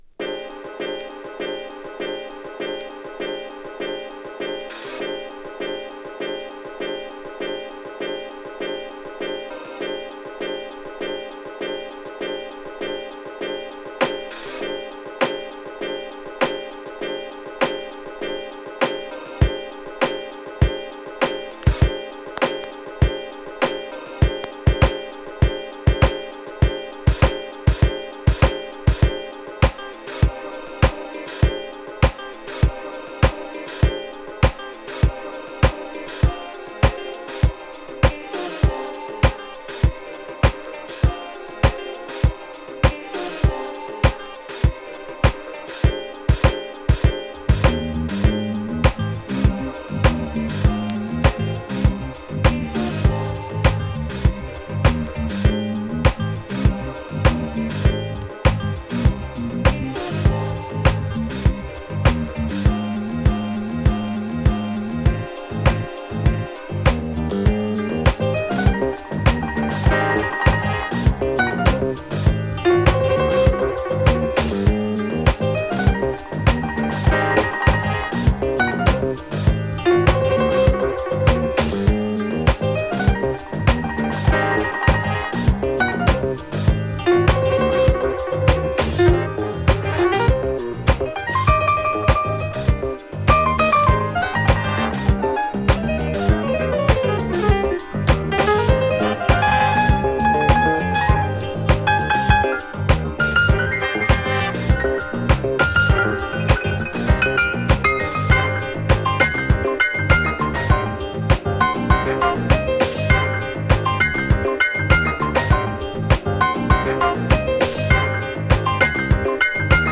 ギター＆スキャットのユニゾンにも心踊るミッドダンサー
ソプラノサックスの美しい調べにバレアリックな輝き魅せる